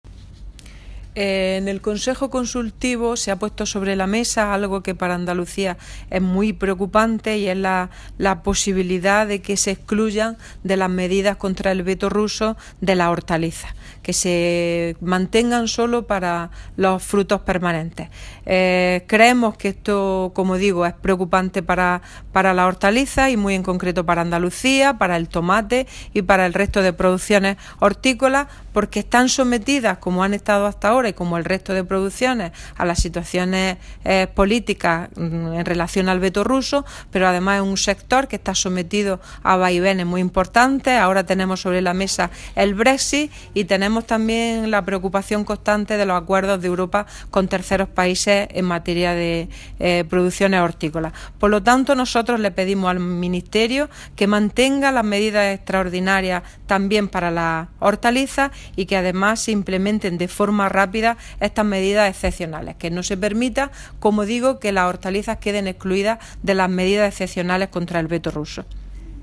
Declaraciones de Carmen Ortiz sobre la Sectorial Extraordinaria de Agricultura y Desarrollo Rural